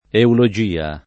eulogia [ eulo J& a ] s. f.